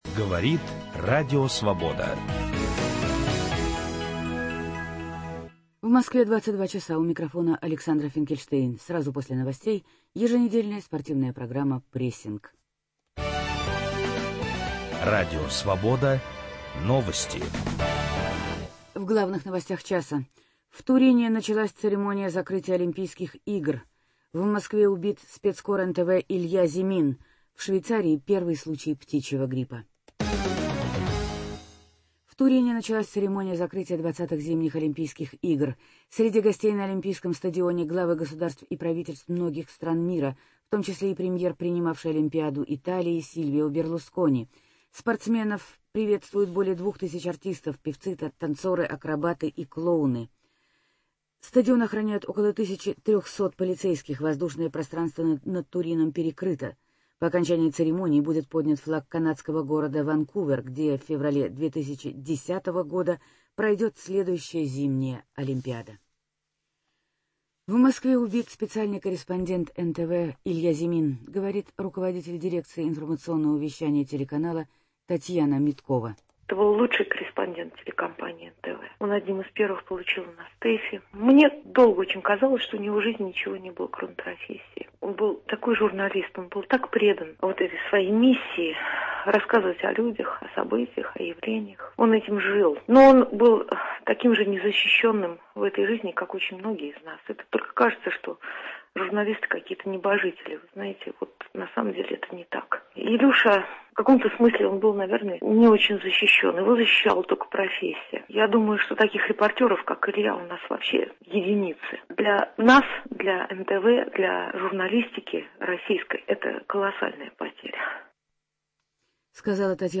Вы услышите голоса чемпионов и призеров Игр, их тренеров и соперников, а также специалистов и аналитиков.